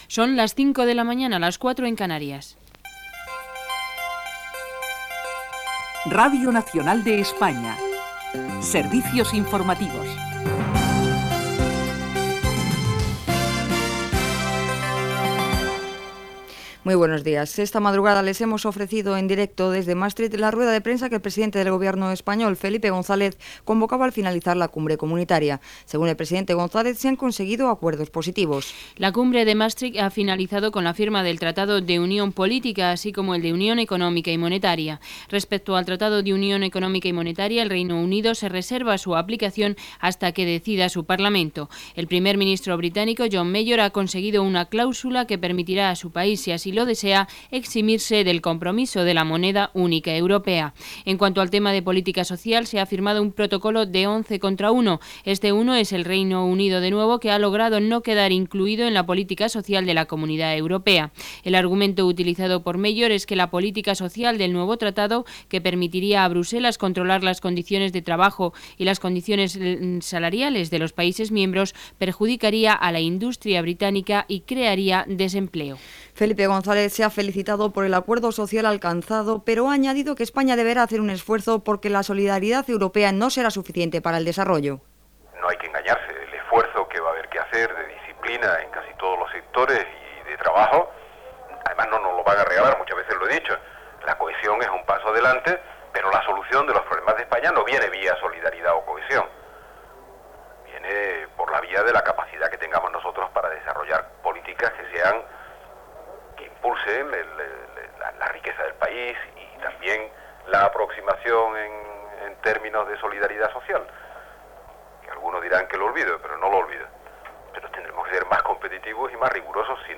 Hora, careta, ha acabat la cimera de Maastricht amb declaracions del president del govern espanyol Felipe Gonzalez, resum informatiu, estat del temps, comiat
Informatiu